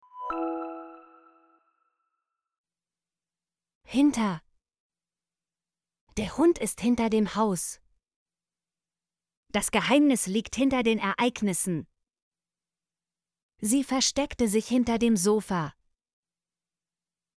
hinter - Example Sentences & Pronunciation, German Frequency List